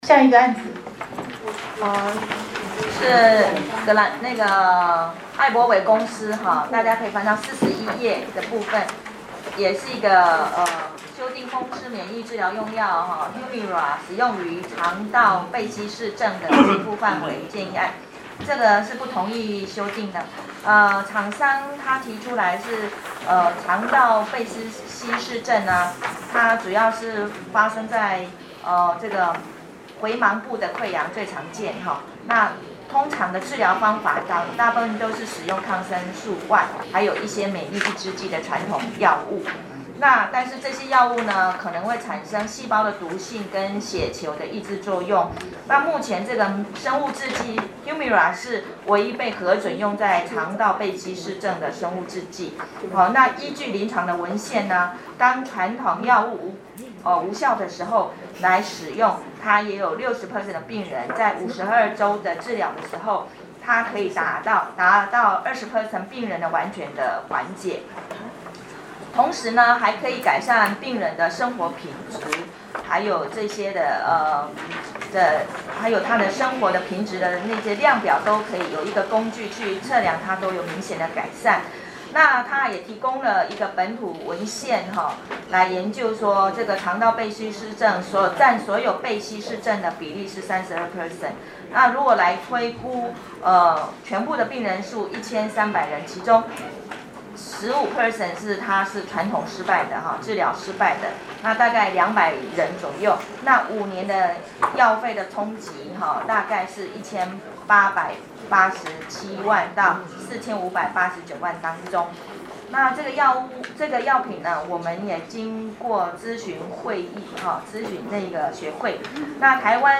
「全民健康保險藥物給付項目及支付標準共同擬訂會議」第24次(106年2月)會議實錄錄音檔